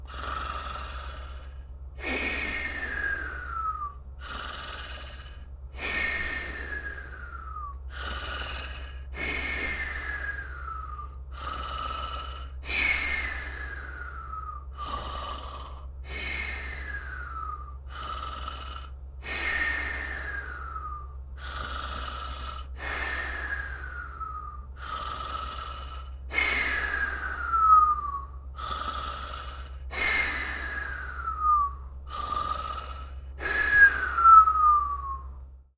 Blue-Snowball Blue-brand cartoon comedy human snore snort whistle sound effect free sound royalty free Funny